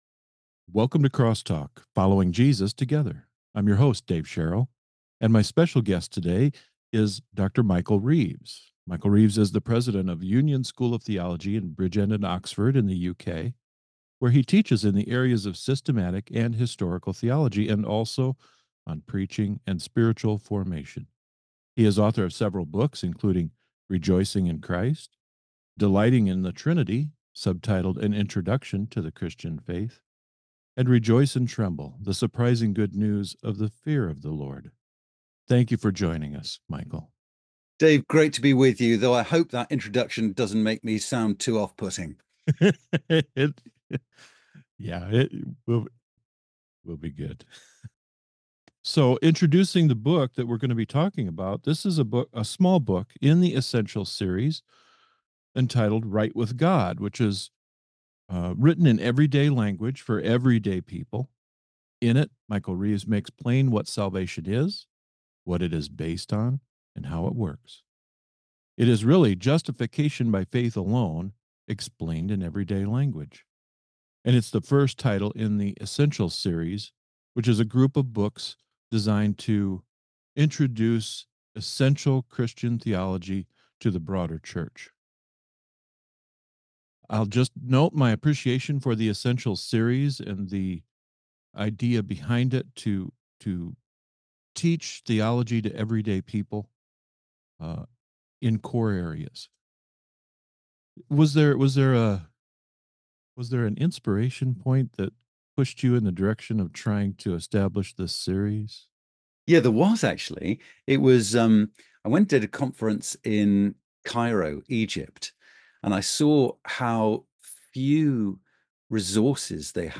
Crosstalk 2.6: Right with God – An Interview